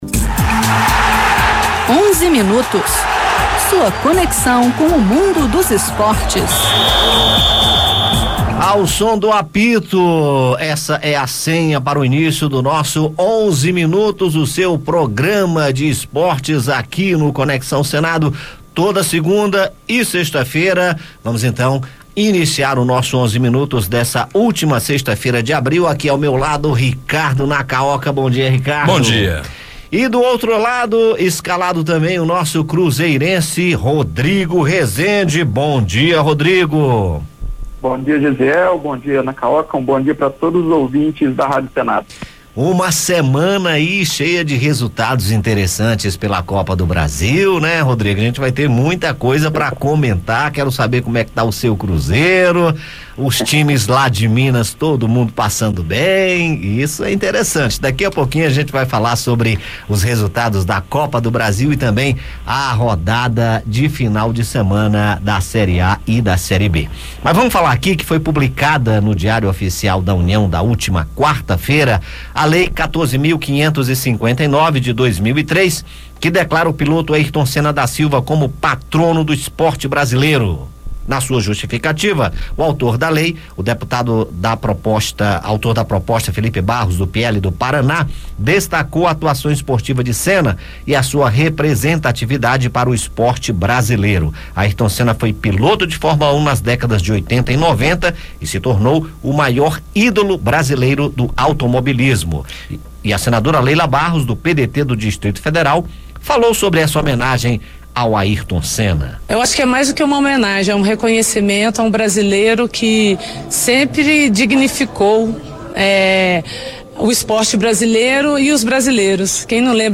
Foi publicada no Diário Oficial da União da última quarta-feira (26) a lei 14.559, de 2023, que declara o piloto Ayrton Senna da Silva como Patrono do Esporte Brasileiro. O autor da proposta, Filipe Barros (PL-PR), destacou a atuação esportiva de Senna e sua representatividade para o esporte brasileiro. E a senadora Leila Barros (PDT-DF) comentou a homenagem ao grande piloto brasileiro.